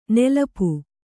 ♪ nīḷu